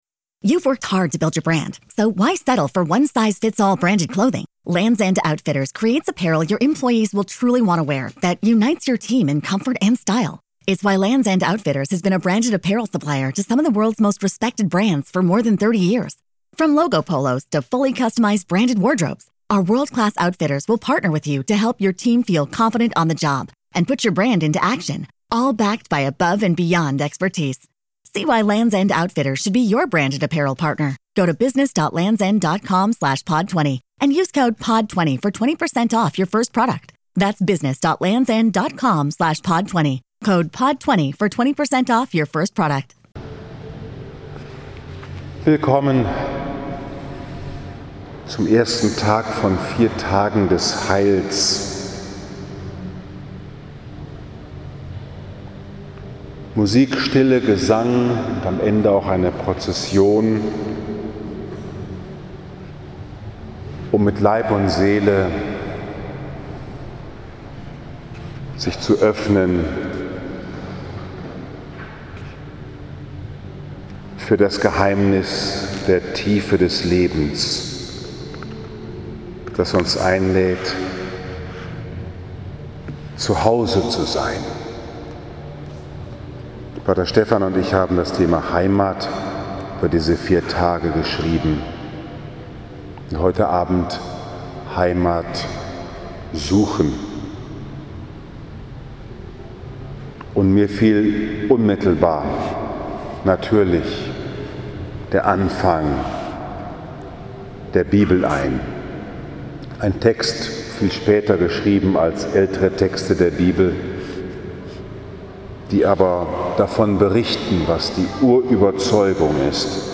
Tage des Heils 2019 in Liebfrauen. Vortrag: Heimat suchen ~ Bruder Paulus´ Kapuzinerpredigt Podcast